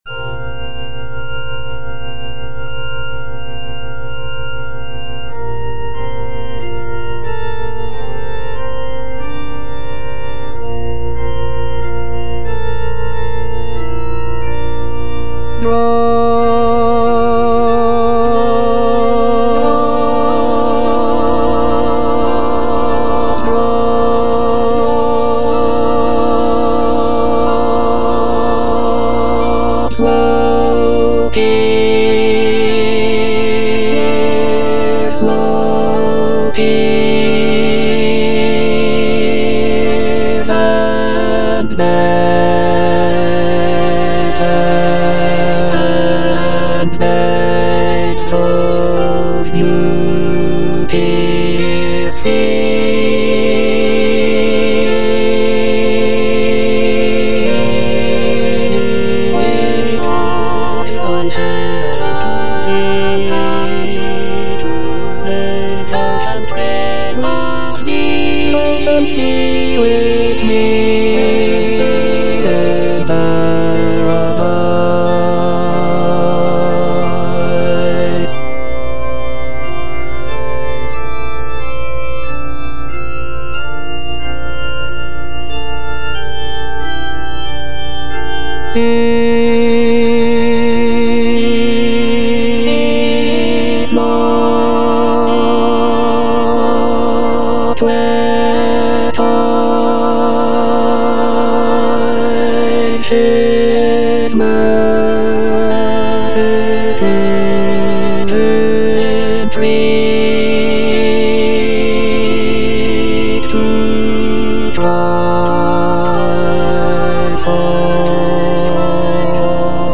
(bass I) (Emphasised voice and other voices) Ads stop